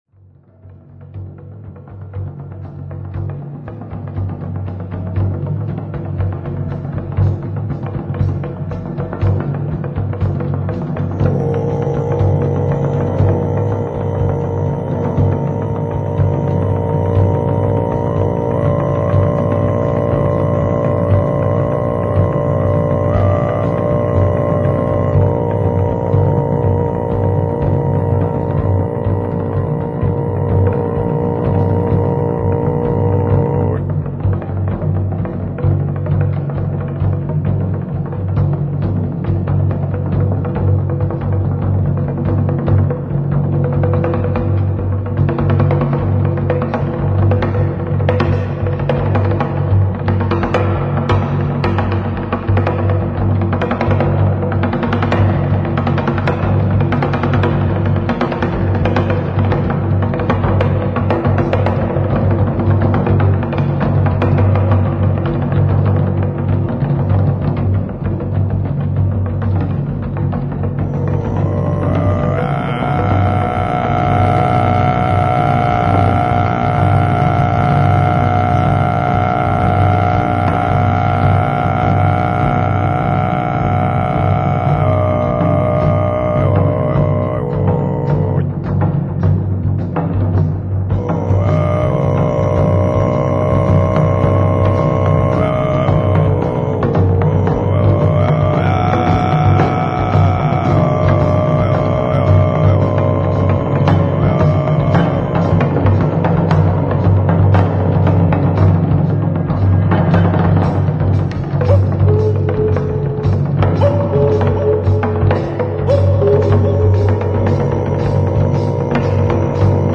Шаман поет песню духов